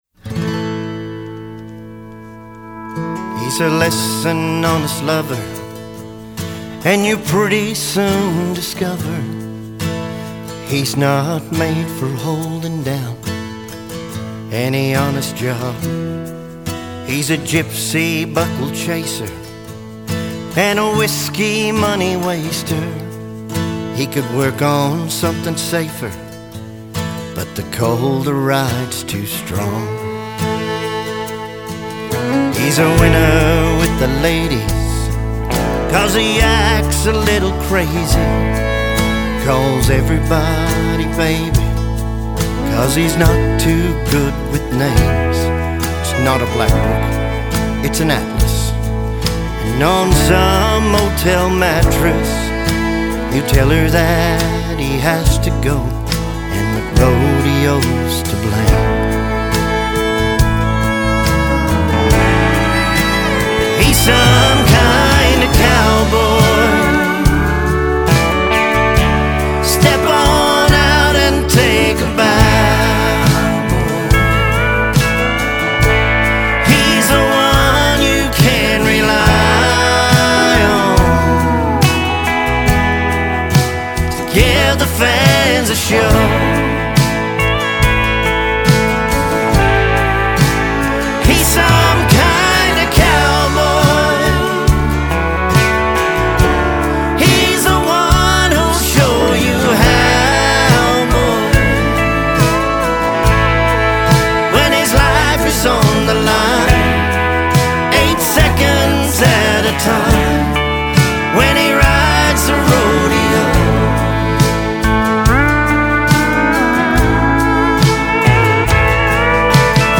definitive traditional sounding country track